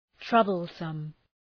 Προφορά
{‘trʌbəlsəm}